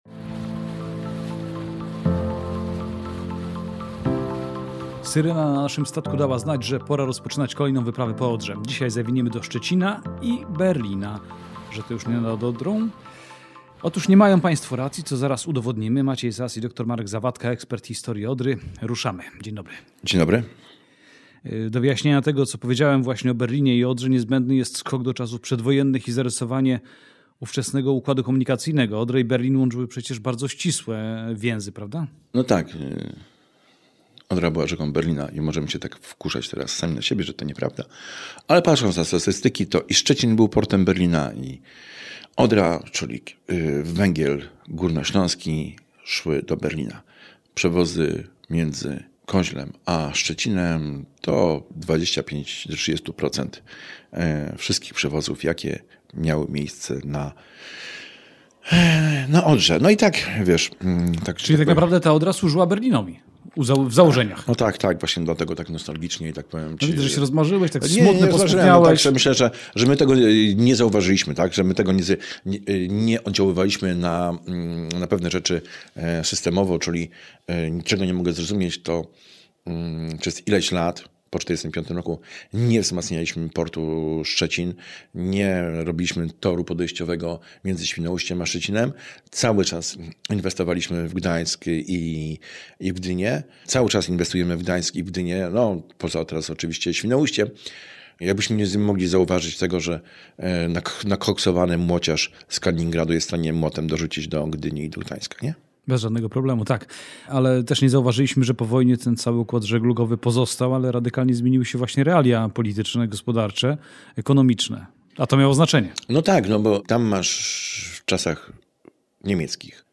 Rozmowa dotyczy tylko roli żeglugi śródlądowej w działalności portu Szczecin.